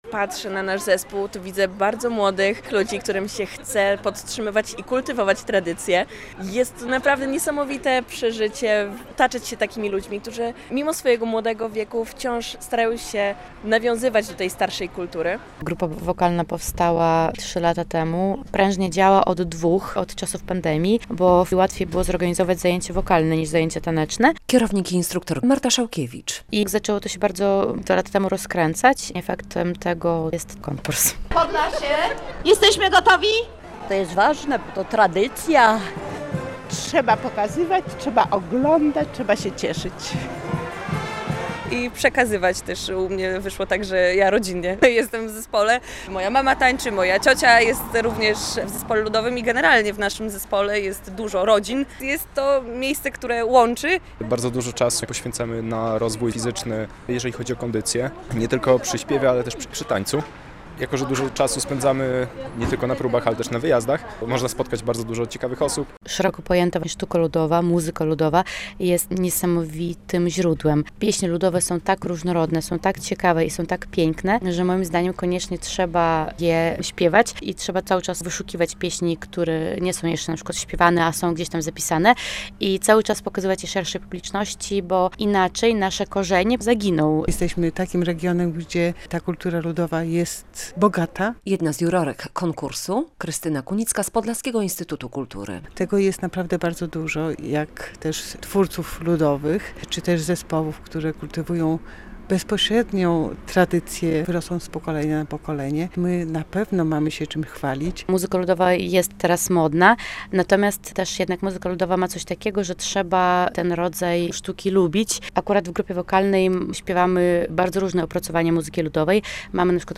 Województwo podlaskie reprezentuje Grupa Wokalna Zespołu Pieśni i Tańca "Kurpie Zielone" - relacja